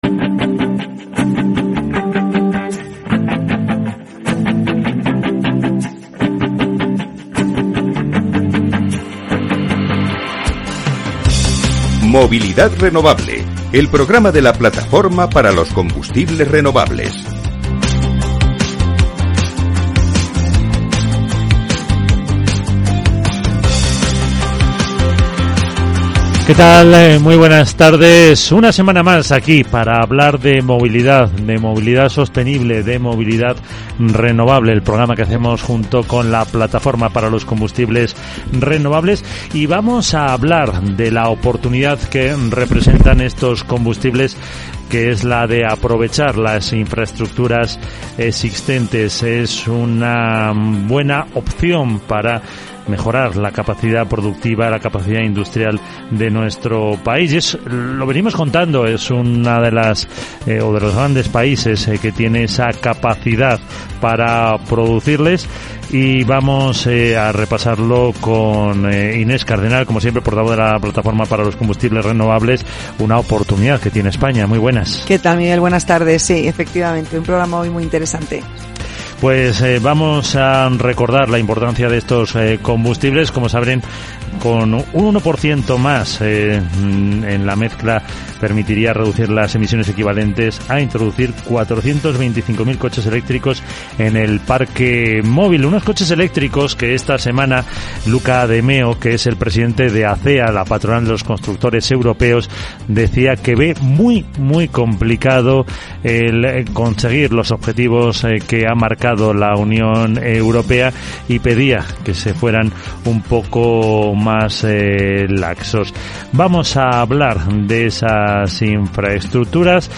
En este espacio van a participar todos los actores implicados desde los productores hasta los consumidores, pasando por distribuidores etc. Media hora de radio enfocada a conocer esta solución inmediata para mejorar el medio ambiente.